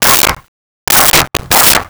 Dog Barking 10
Dog Barking 10.wav